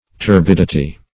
Turbidity \Tur*bid"i*ty\, n.